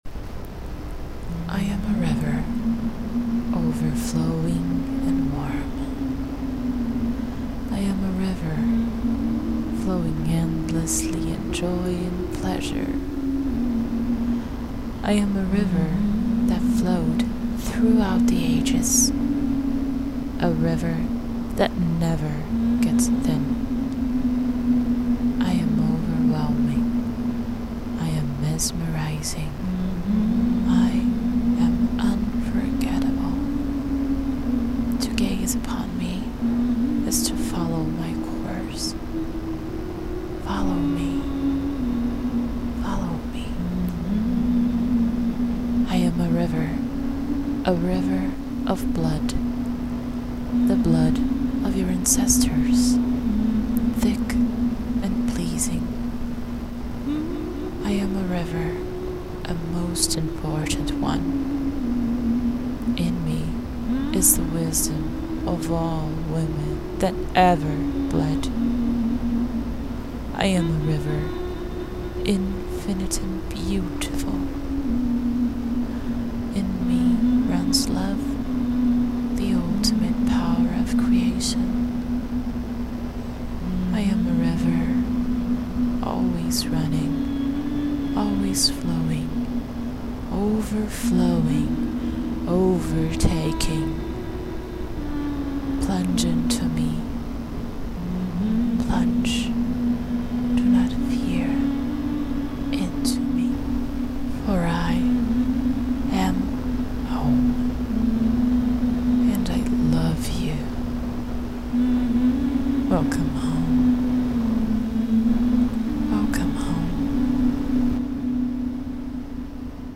This is not a song, its a text, it’s the words of the Lady of the Blood Ocean, the Goddess of menstruation, the Sacred Feminine in it’s most intense and unique power.
I also had this song, The River, “playing” in the background of my mind as I wrote and read those words, so I recorded the humming of it and put it in the background so it wouldn’t distract from the words.